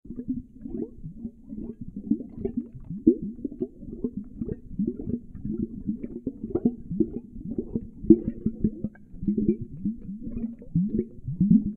gurgle-bubble-liquid.ogg